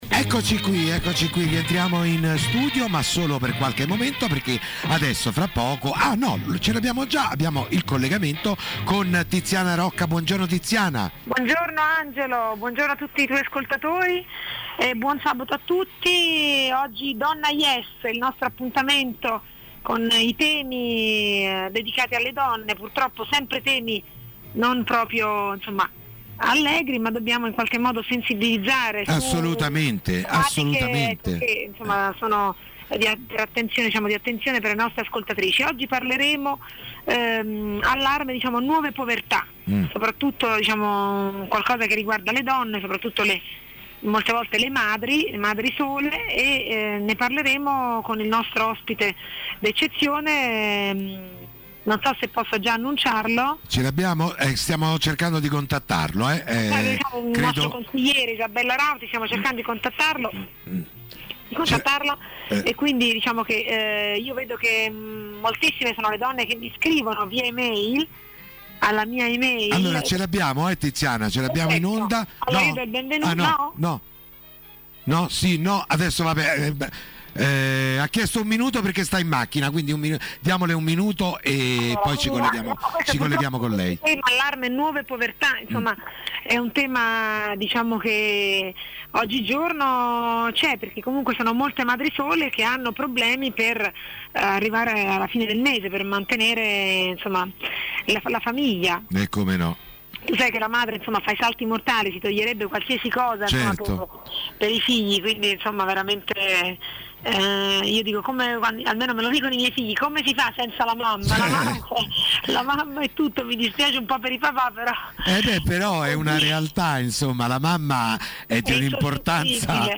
Radio ies (99,8 FM) – Intervistata